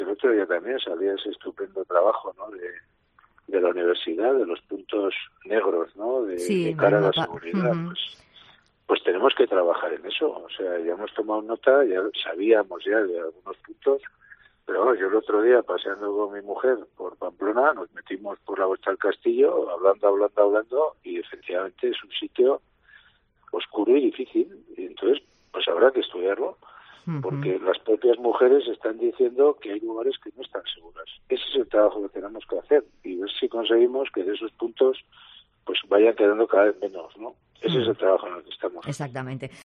ENRIQUE MAYA, ALCALDE DE PAMPLONA